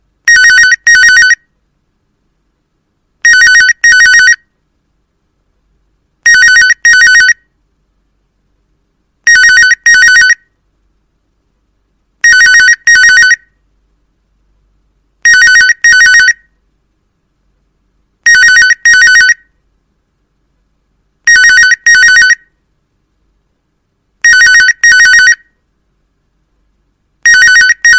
Звонок Старого телефона Motorola